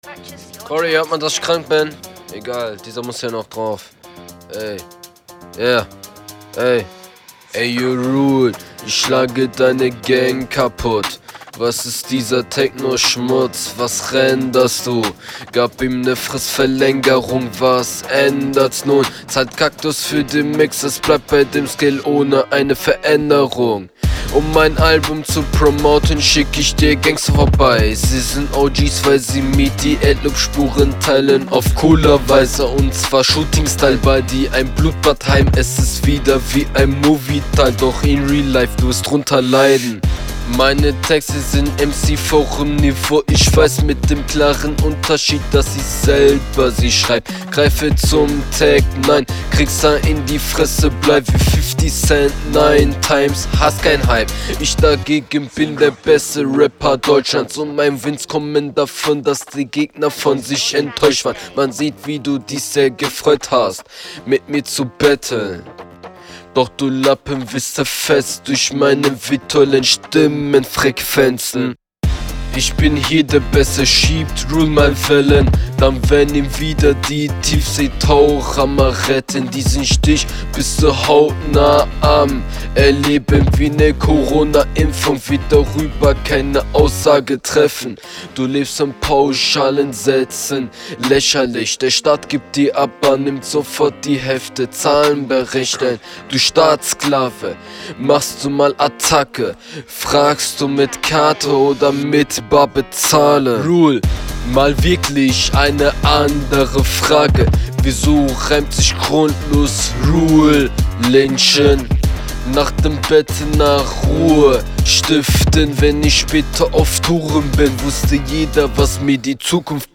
flow nich mehr als standart